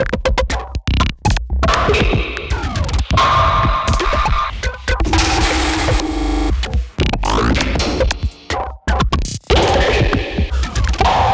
(yes , thats a real toilet sampled 20min ago).